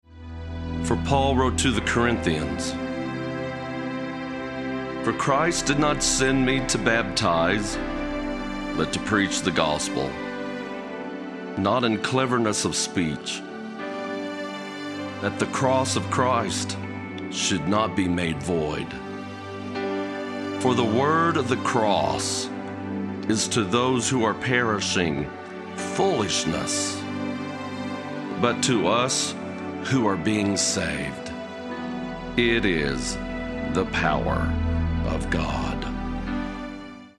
Background rhythms with flowing melodies